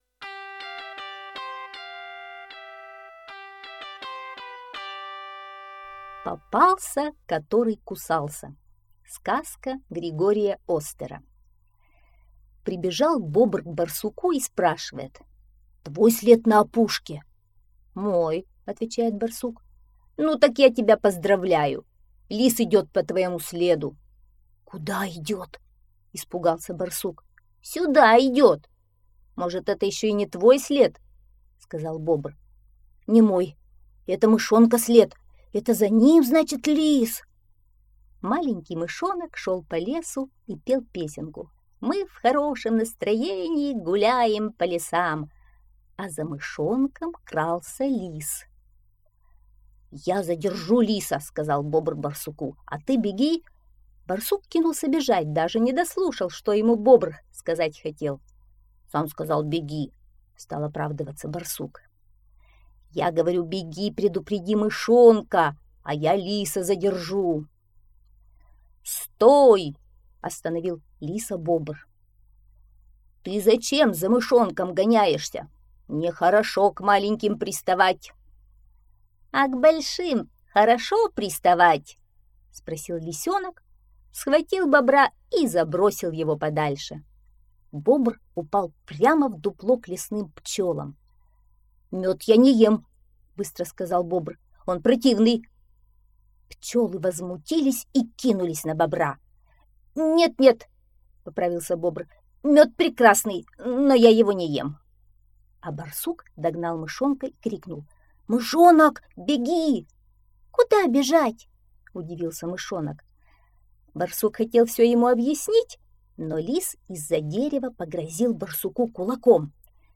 Попался, который кусался - аудиосказка Остера Г. Интересная история про храброго и находчивого мышонка, который в одиночку смог...